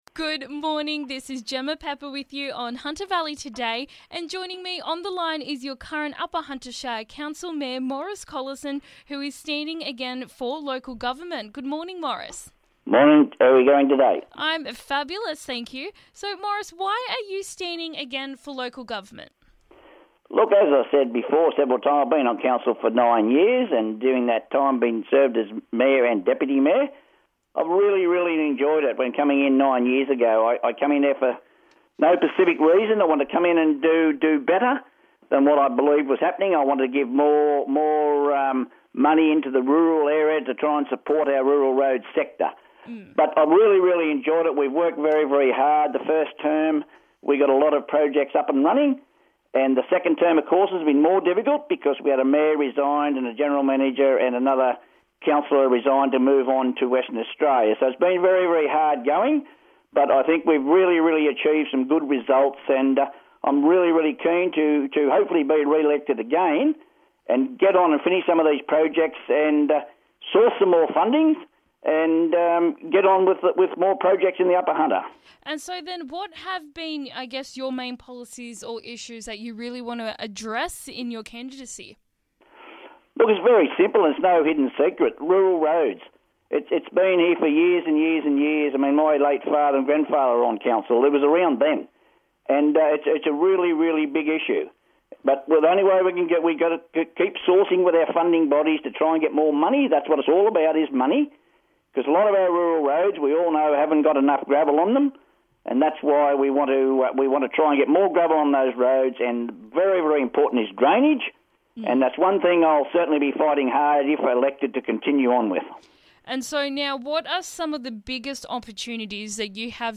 Current Upper Hunter Shire Council Mayor discusses his campaign for the upcoming local government election.